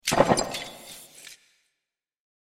دانلود آهنگ ربات 63 از افکت صوتی اشیاء
جلوه های صوتی
دانلود صدای ربات63 از ساعد نیوز با لینک مستقیم و کیفیت بالا